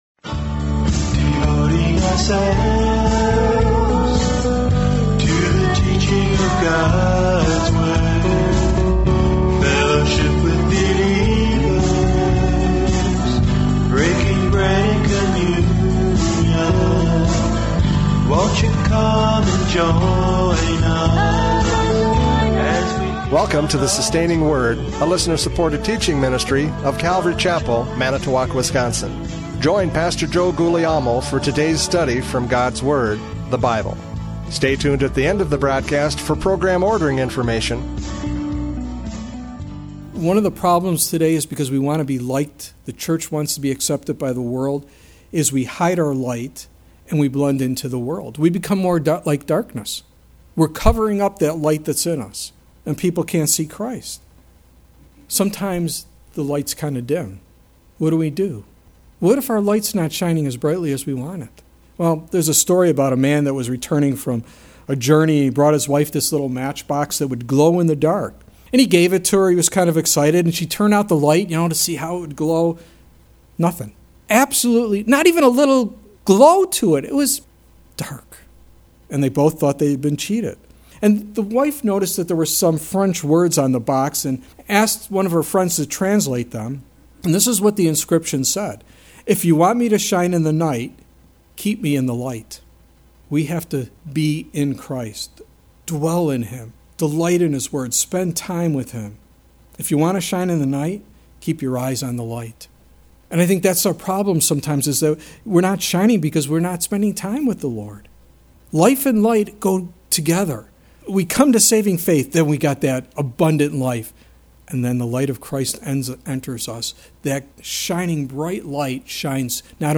John 1:4-5 Service Type: Radio Programs « John 1:4-5 The Battle of Light and Darkness!